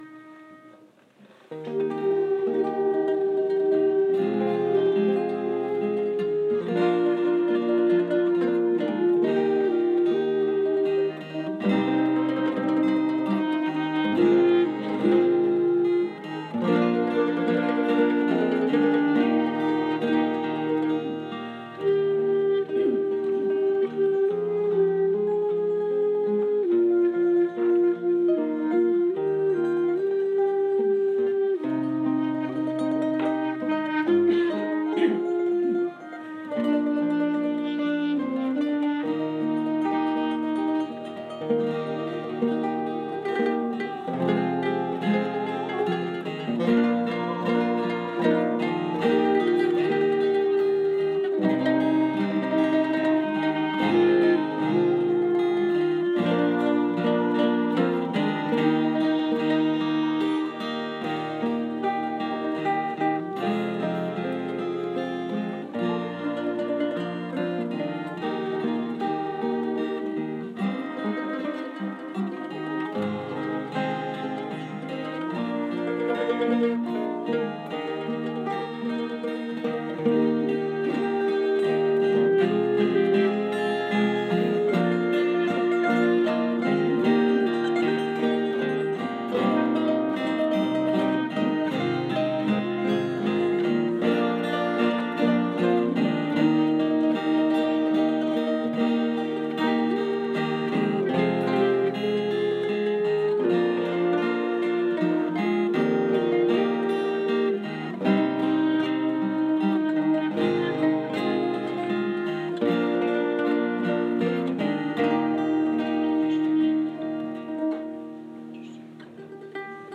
sunday Instrumental